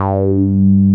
RUBBER G3 F.wav